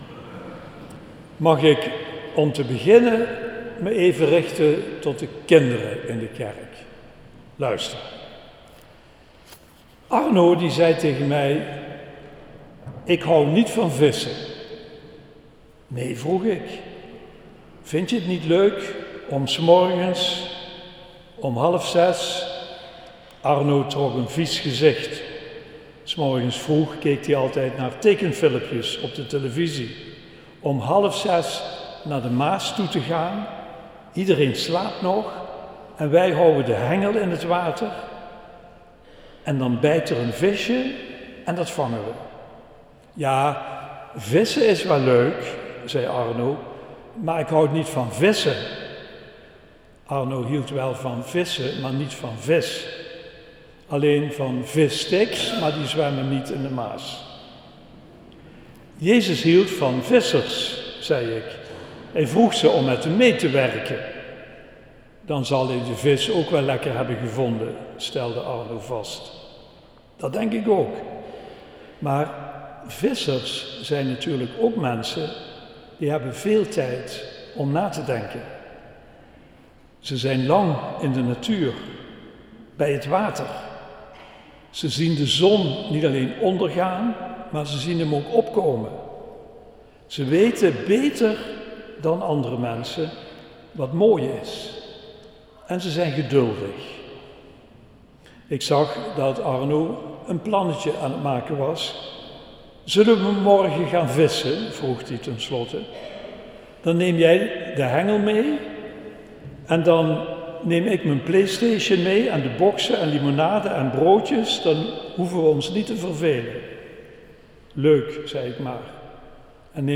De datum waarop de preek gehouden is ligt gewoonlijk een week later